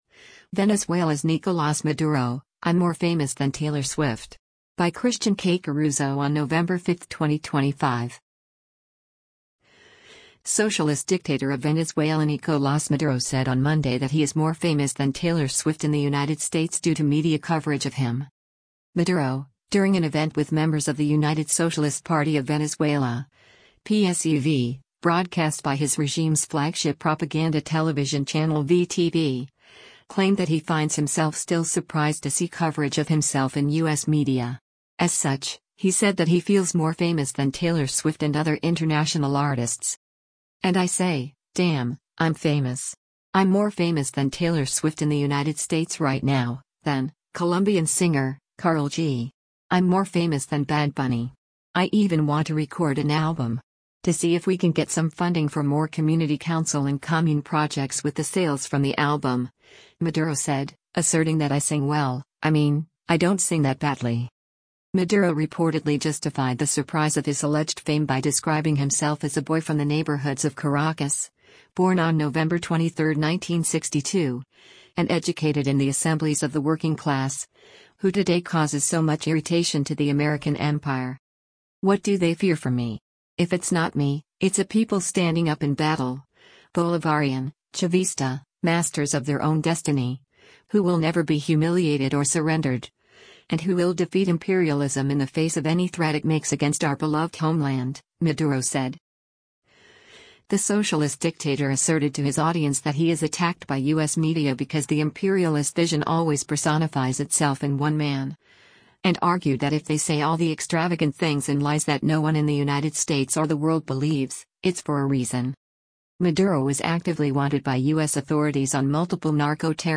Maduro, during an event with members of the United Socialist Party of Venezuela (PSUV) broadcast by his regime’s flagship propaganda television channel VTV, claimed that he finds himself “still surprised” to see coverage of himself in U.S. media.